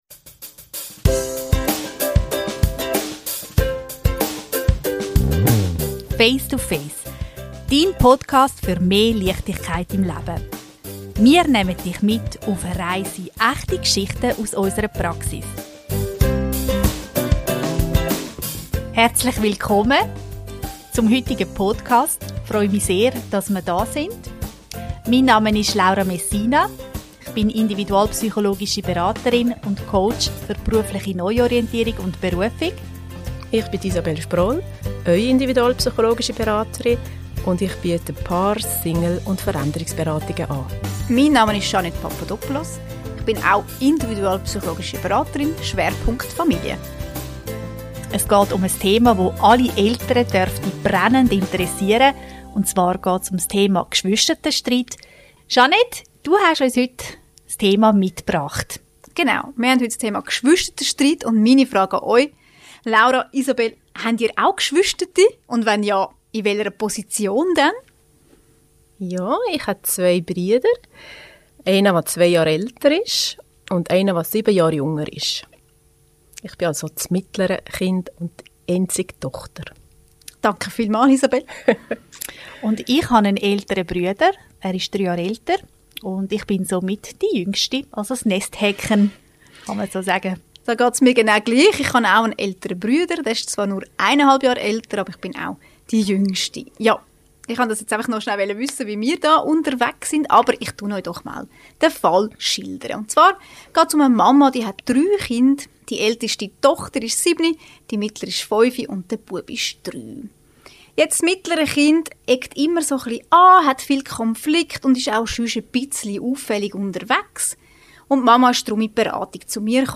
In dieser Folge sprechen die drei individualpsychologischen Beraterinnen